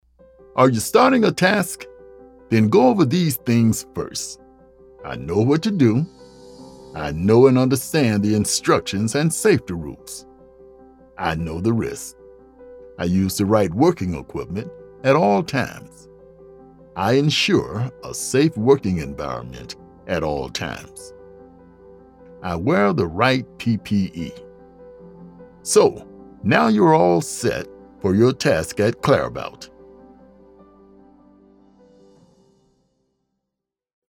Explainer & Whiteboard Video Voice Overs | Instructional YouTube Voice Over | Male & Female
0316EXPLAINER_DEMO--STARTING_A_TASK--CD.mp3